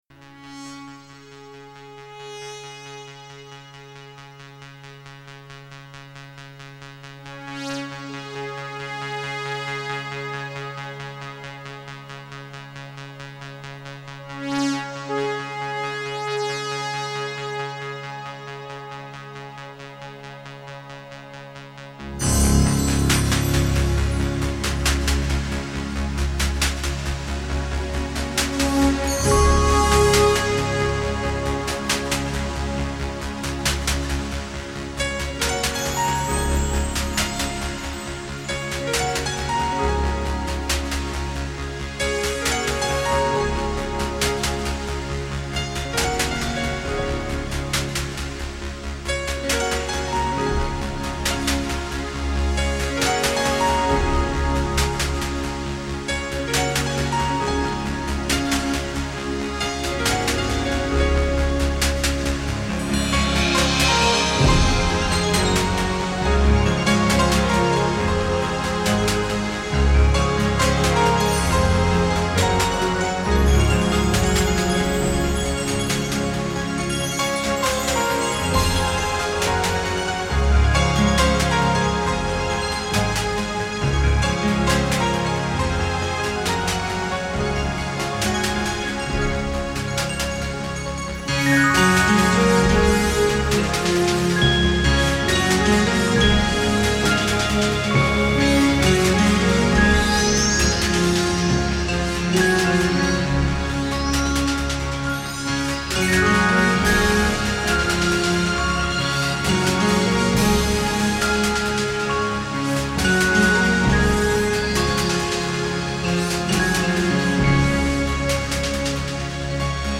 BPM: 136.400 Offset: 22,194